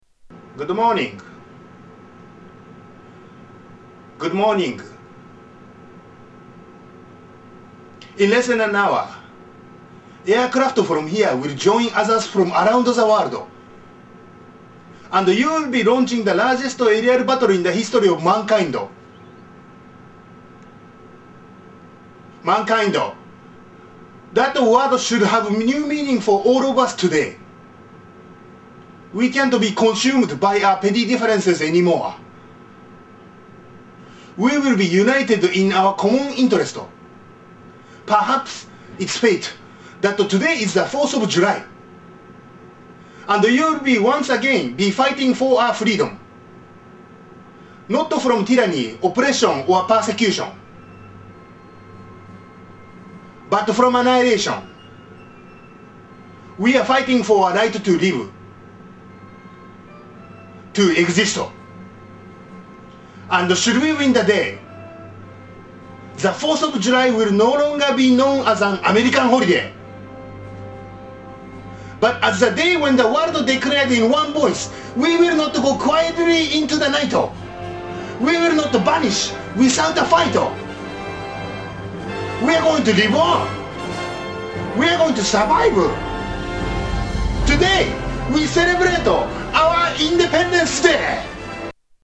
Tags: Travel Japan Japanese Accent Japanese accent